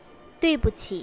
対不起 トゥイ ブ チ dui4 bu qi3